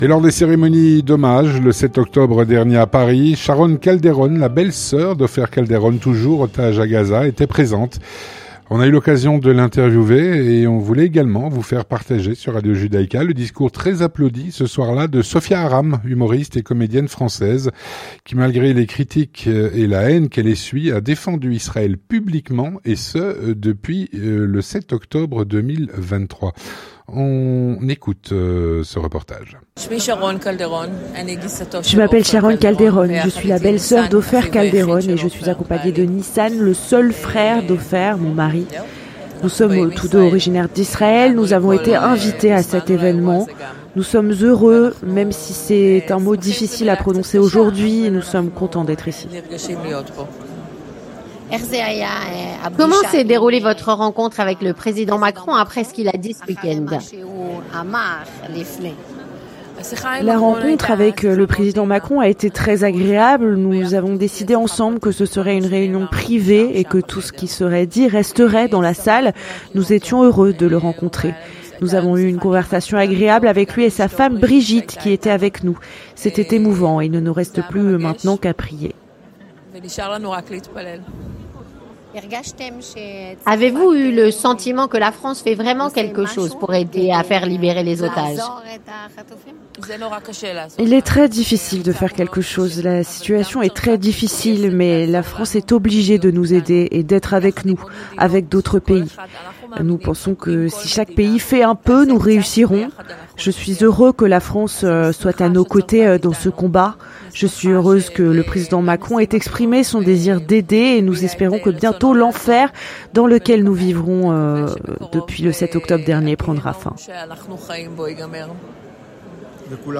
Témoignage
Nous avons pu l’interviewer. Et on voulait également vous faire partager le discours, très applaudi ce soir-là, de Sophia Aram, humoriste et comédienne française, qui, malgré les critiques et la haine qu’elle essuie, a toujours défendu Israël publiquement depuis le 7 octobre 2023.
Un reportage sur place, à Paris, de